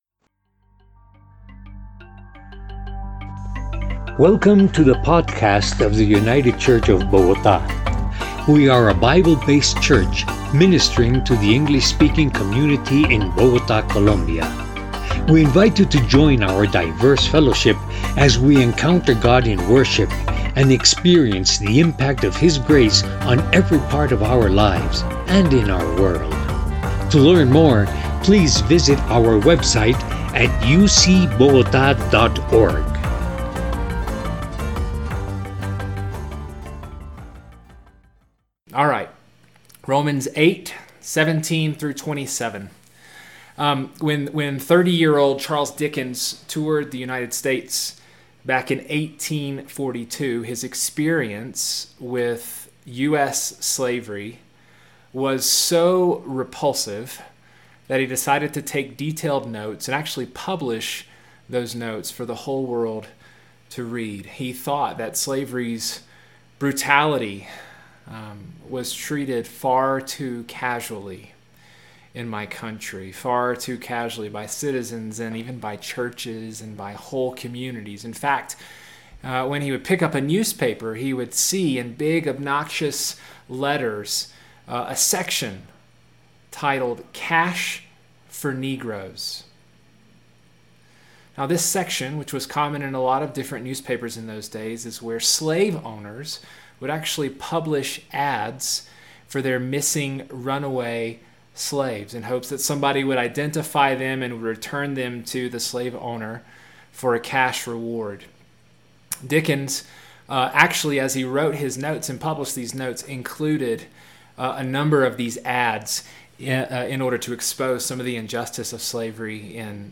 Groaning for Glory – United Church of Bogotá